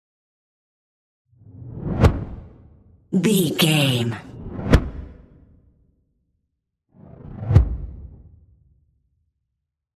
Whoosh flap x3
Sound Effects
dark
tension
whoosh